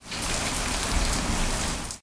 rain_2.ogg